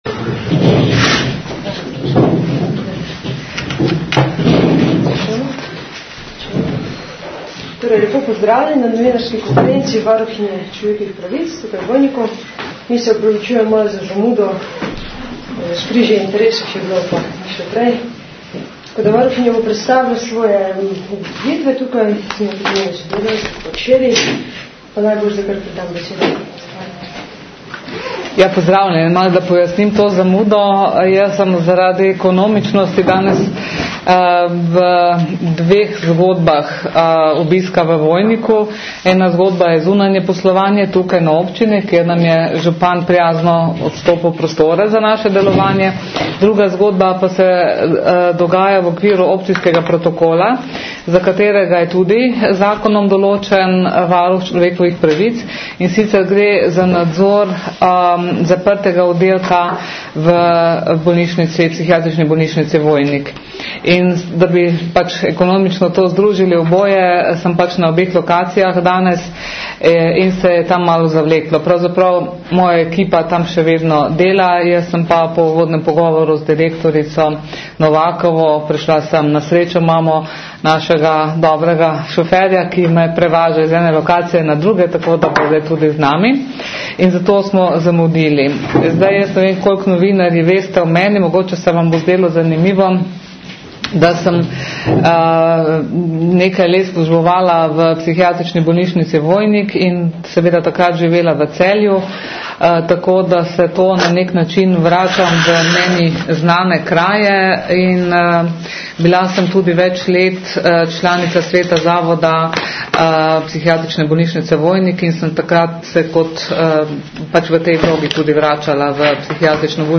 Zvočni posnetek novinarske konference (mp3, 5.02 MB)